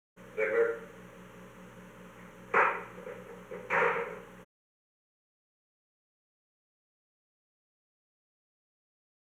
Conversation: 433-026
Recording Device: Old Executive Office Building
The Old Executive Office Building taping system captured this recording, which is known as Conversation 433-026 of the White House Tapes.
The President talked with the White House operator.